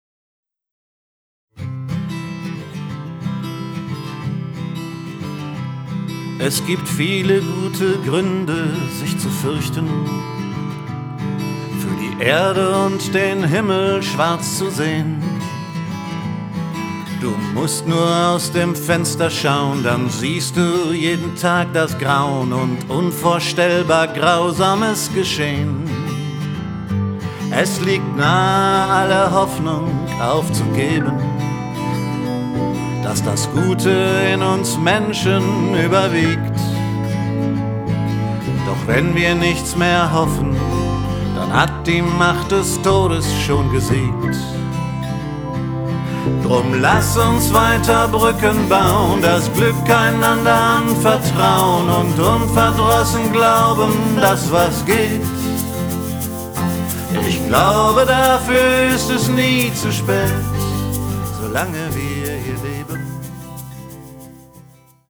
Piano, Keyboards
Percussion
Vocals, Gitarren